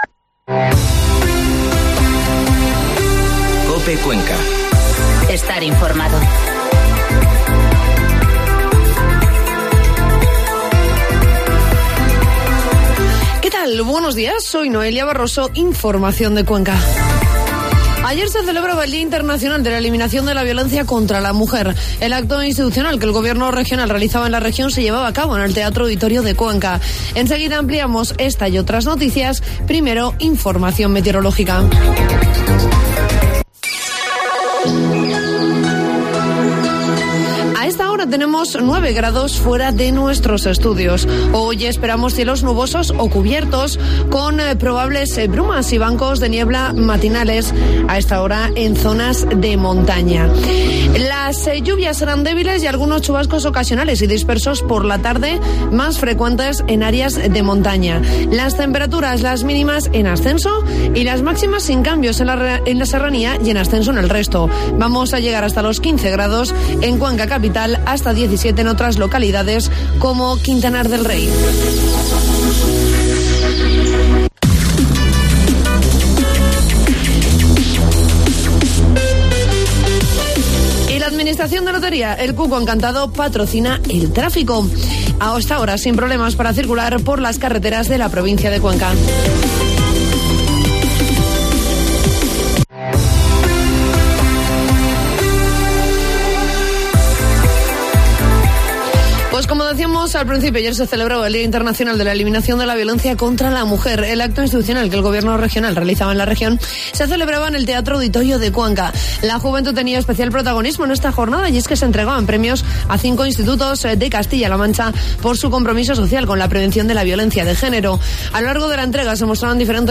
Informativo matinal COPE Cuenca 26 de noviembre